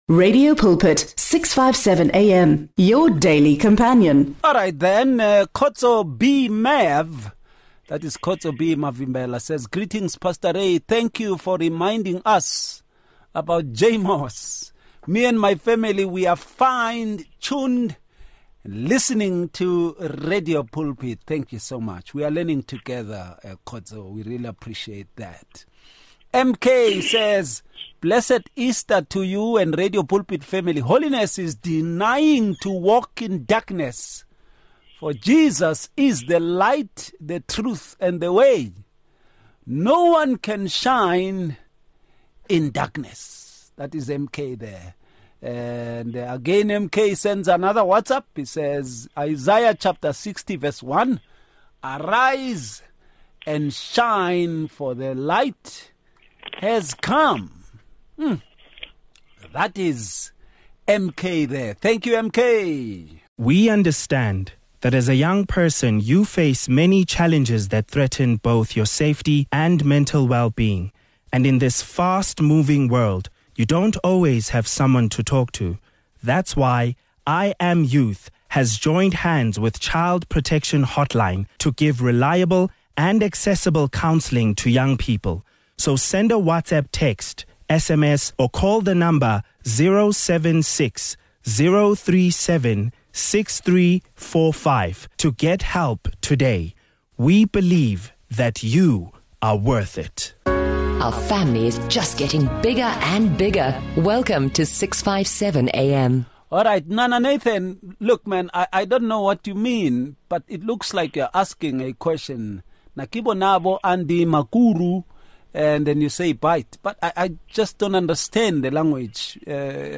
They emphasize that holiness involves both moral purity and a deep spiritual commitment to God’s will. The panelists share insights on how holiness is cultivated through daily spiritual disciplines and reliance on the Holy Spirit.